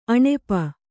♪ aṇepa